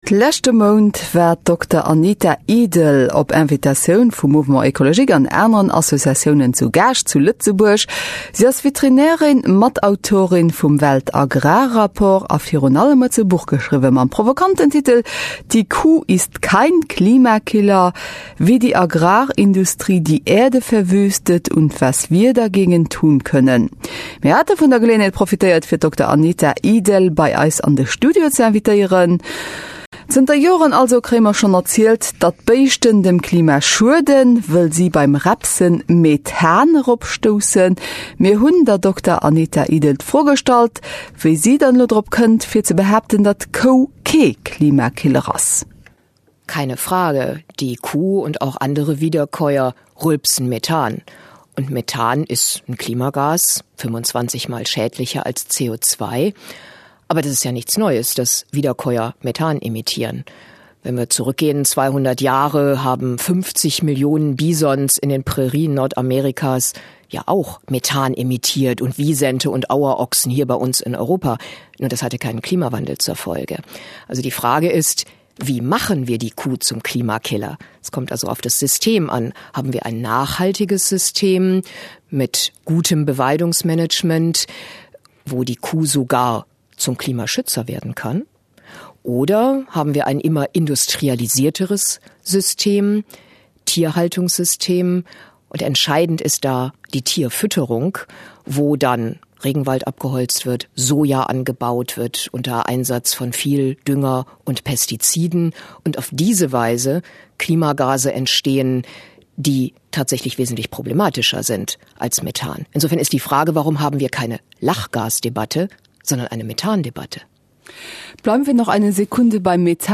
820_interview_luxemburg.mp3